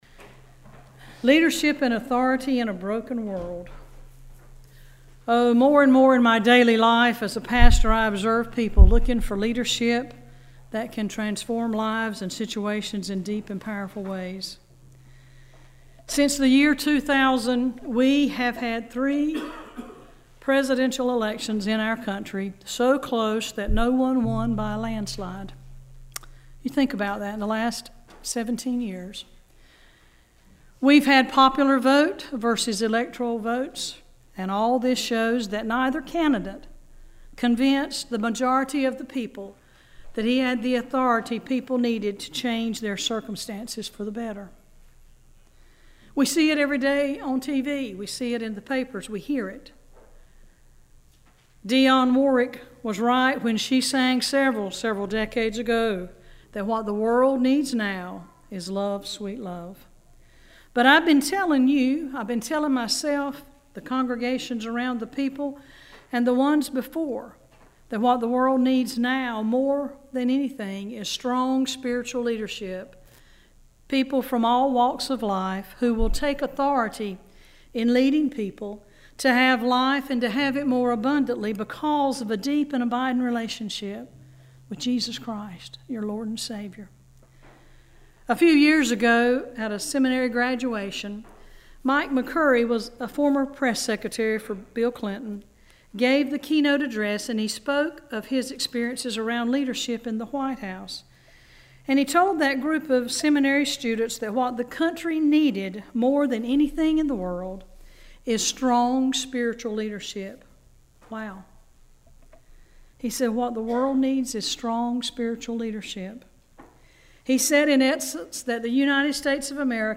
1-28-18-sermon.mp3